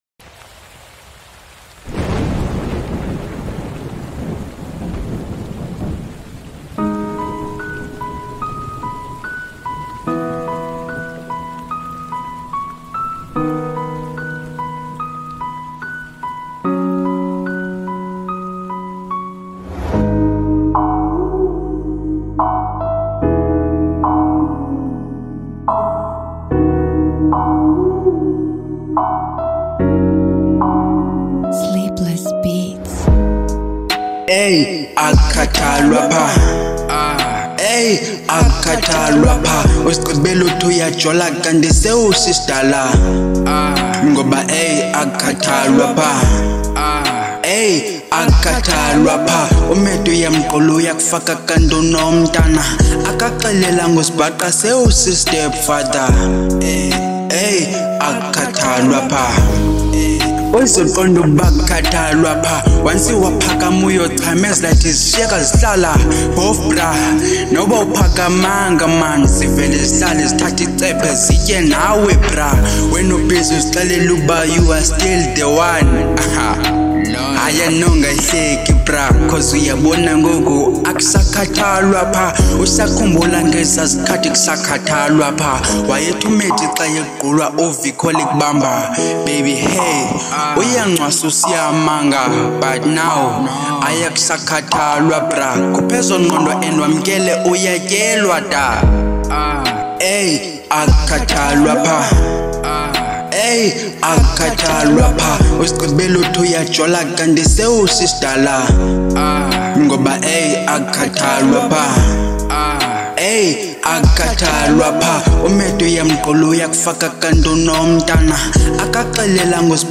02:48 Genre : Hip Hop Size